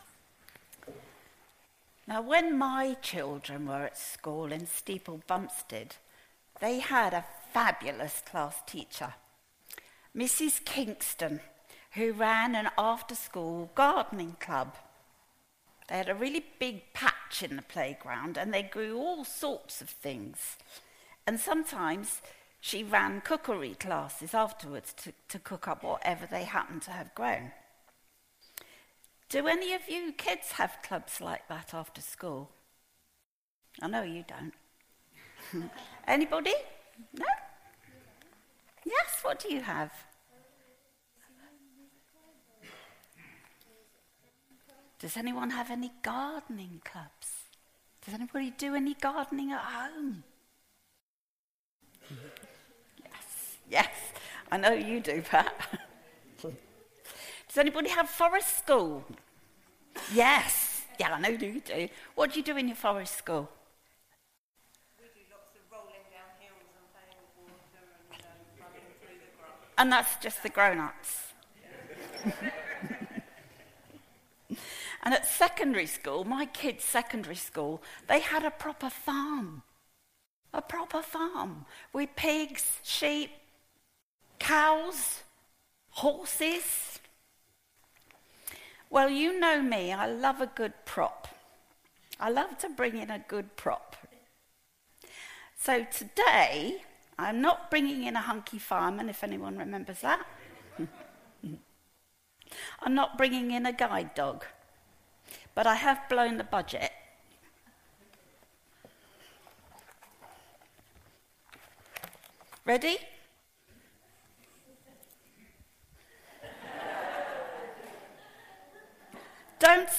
Both the All Age talk and the sermon are available as an audio file.
07-28-AA-talk-sermon.mp3